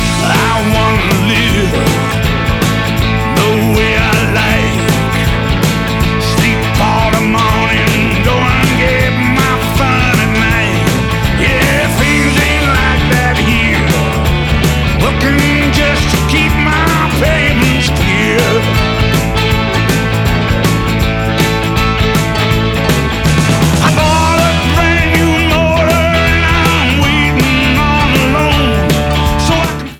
Sound Samples (All Tracks In Stereo)